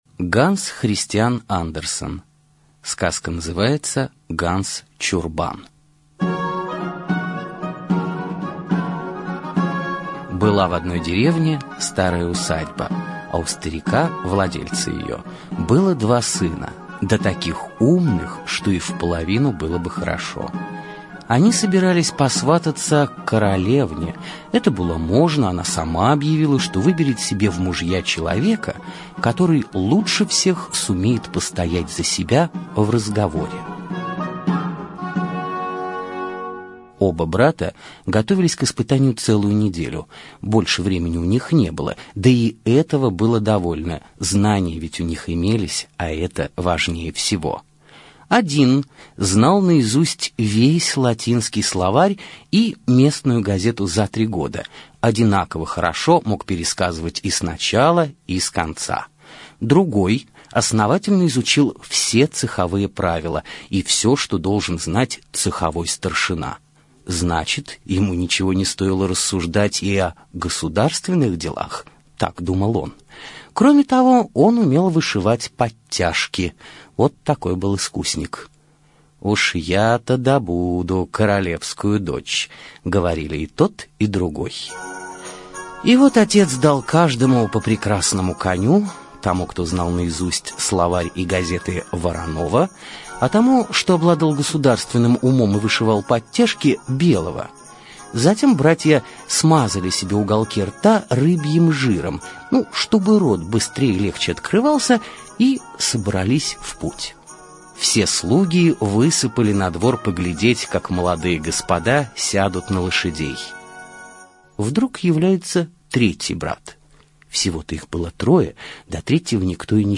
Ганс Чурбан - аудиосказка Андерсена. Сказка Андерсена о трех сыновьях, которые поехали свататься к королеве.